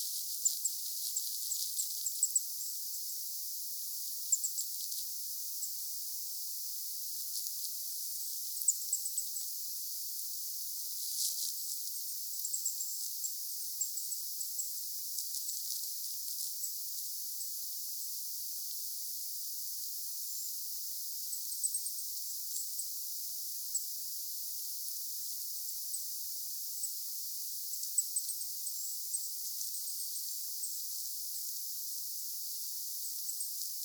hippiäisiä oikein pieni parvi
jonkinlaisia_hippiaisia_pieni_parvi_oikein.mp3